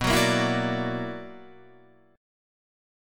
B Minor Major 11th